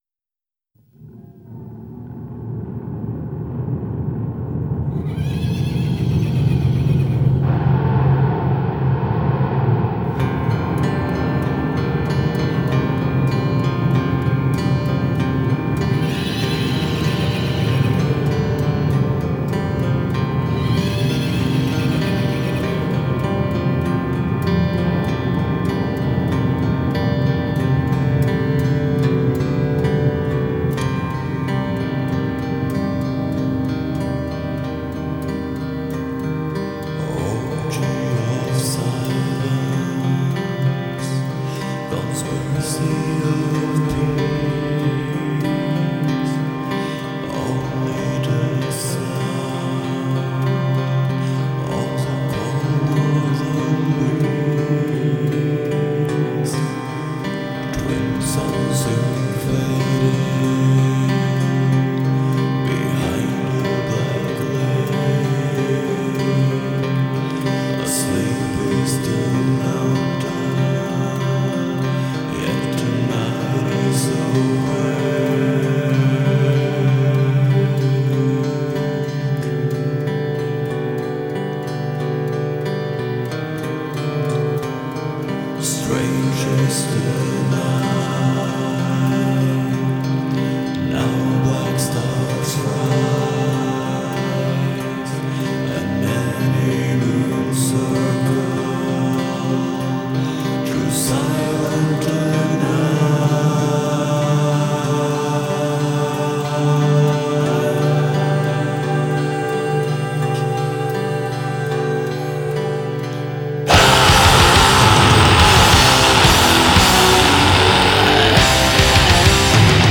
بلک متال نروژی
ریف گیتار بسیار خشن و تند و تیز با ووکال هارش